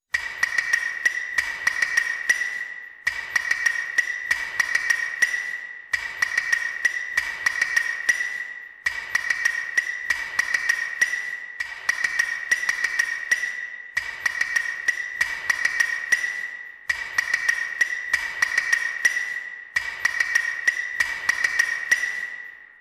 Tiếng rao bán Hủ Tiếu Gõ kêu lóc cóc ngày xưa…
Thể loại: Tiếng con người
Description: Tiếng rao bán hủ tiếu gõ ngày xưa vang vọng giữa phố khuya, âm thanh “lóc cóc”, “xực tắc” ngân dài từ hai thanh gỗ đen bóng gõ vào nhau, giọng rao thân thuộc Hủ tiếu gõ. Tiếng gõ khô khan mà ấm áp, vang vọng như nhịp sống của phố nhỏ, gợi nhớ ký ức tuổi thơ, những đêm khuya đói bụng chờ tiếng rao thân quen. Âm thanh đặc trưng ấy – “lóc cóc”, “tắc tắc”, “gõ gõ” – như một hiệu ứng âm thanh mộc mạc, chân thật, dùng làm sound effect đầy hoài niệm cho video về Sài Gòn xưa.
tieng-rao-ban-hu-tieu-go-keu-loc-coc-ngay-xua-www_tiengdong_com.mp3